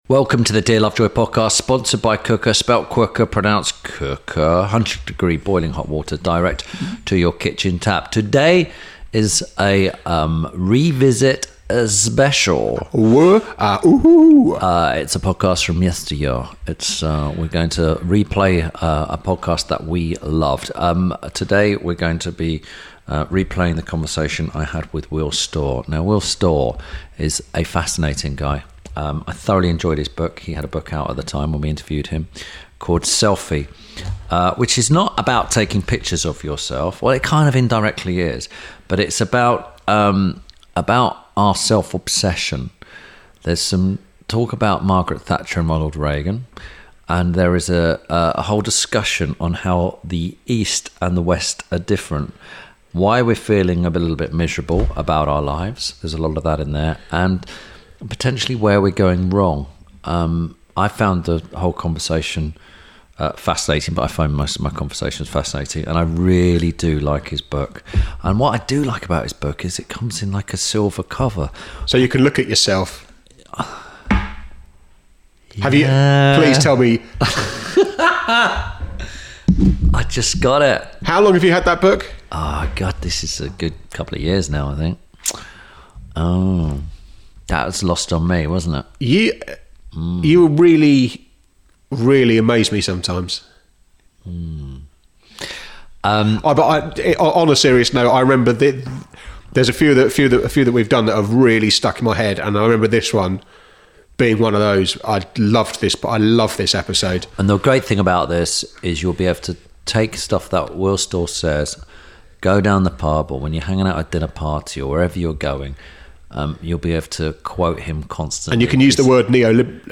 - INTERVIEW SPECIAL
This week Tim Lovejoy talks to writer Will Storr about his book ‘Selfie’. Tim and Will discuss self obsession, why you should remind your children they are only human and how we can blame all of social media on the ancient Greeks.